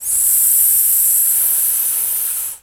pgs/Assets/Audio/Animal_Impersonations/snake_hiss_05.wav at master
snake_hiss_05.wav